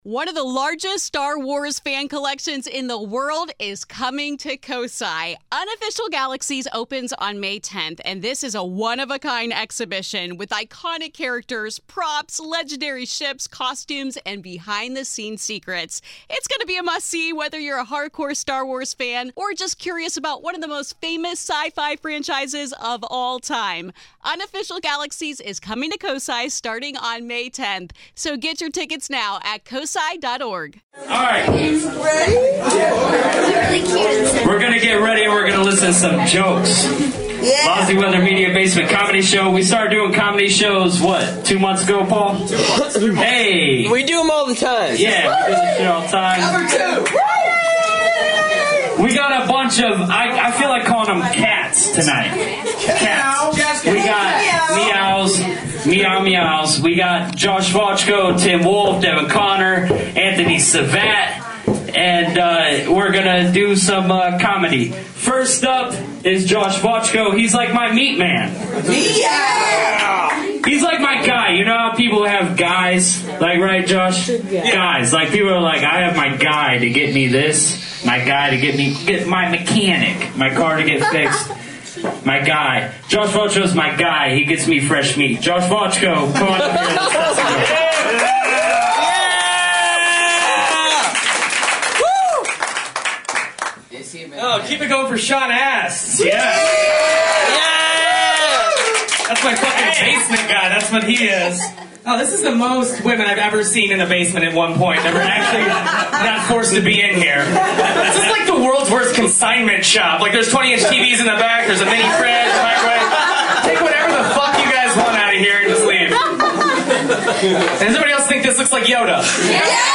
The 4 microphone high quality set up that was live streamed that night and planned for this podcast is still lost for now, but through multiple videos, the ENTIRE show was unearthed and here for listening.
This podcast will give more of a “fly on the wall” aspect to a basement comedy show. No filter, No audio tricks, just raw audio taken from videos in the crowd.